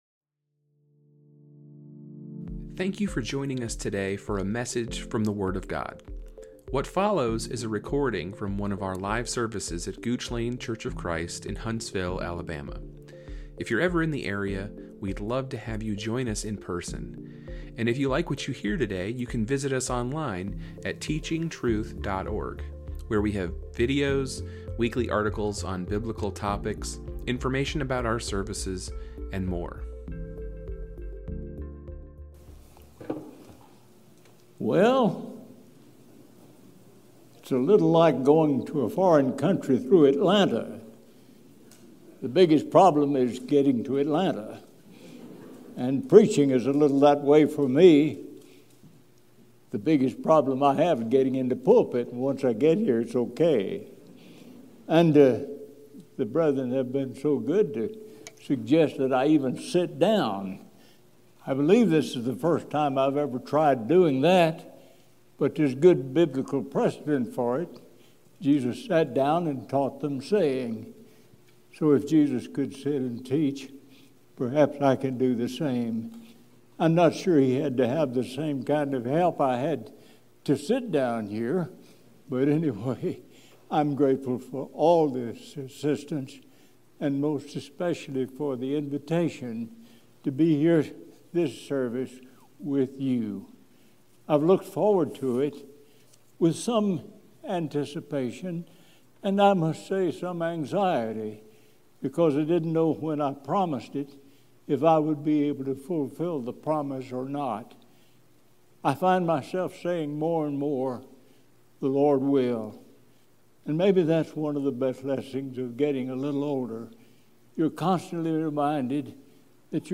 The first lesson in our 2024 Spring Series, presented by guest speaker